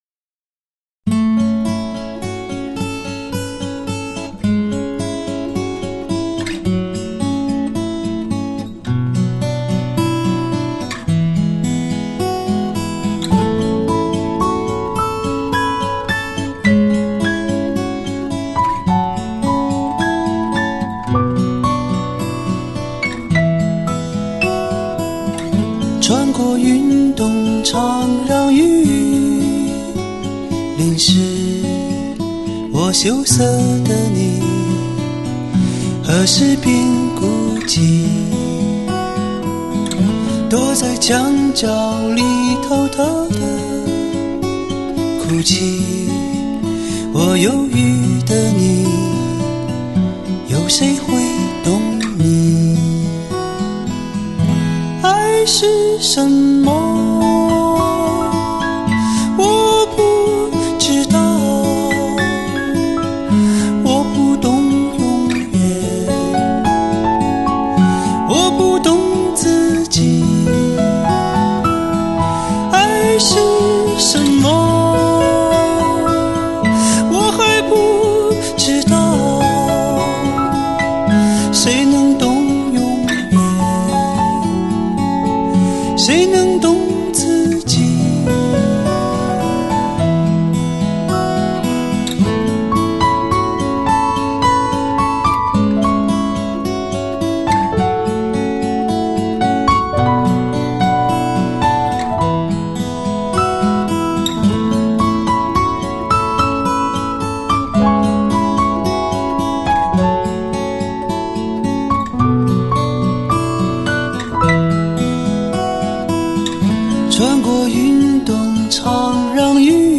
音乐风格: POP-FOLK